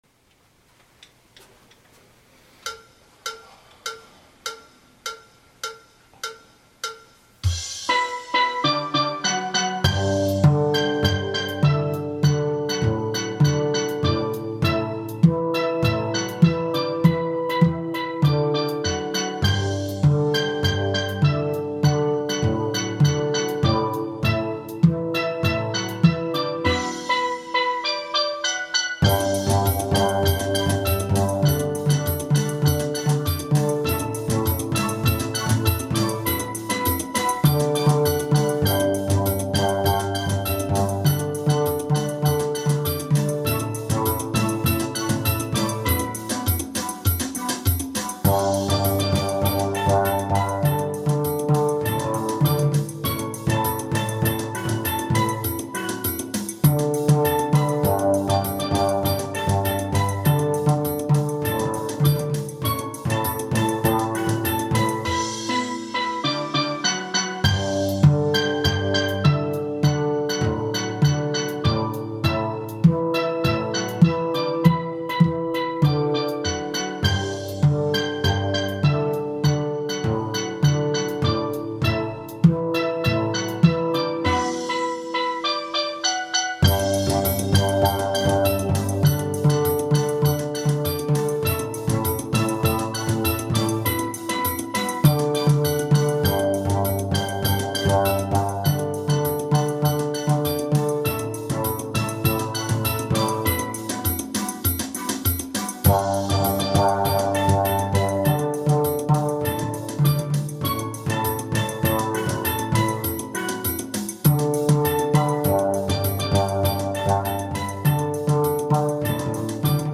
Kaducia Steel Band
demi refrain et fin ralentie.
Basse Magic Vid .mp3